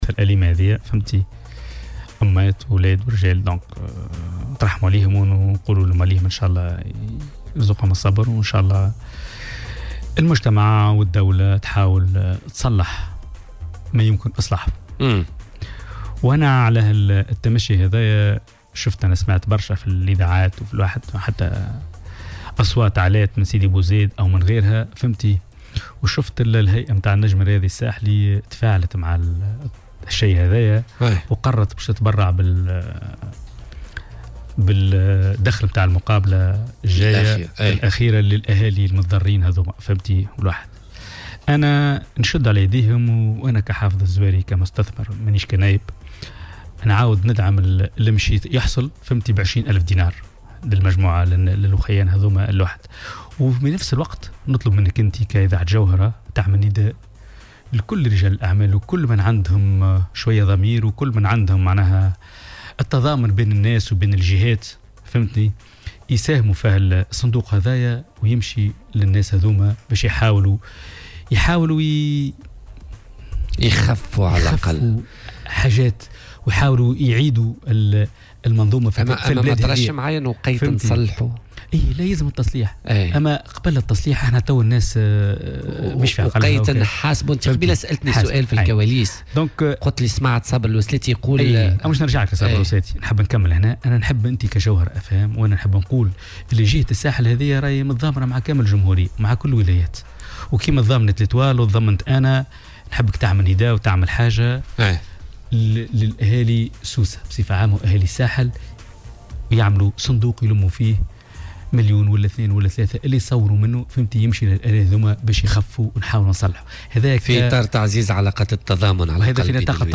وأعلن ضيف "بوليتيكا" على "الجوهرة أف أم" أنه سيساهم من جانبه بمبلغ قيمته 20 ألف دينار في المساعدات التي ستقدم لعائلات الضحايا والتي أطلقتها جهات مختلفة على غرار فريق النجم الرياضي الساحلي.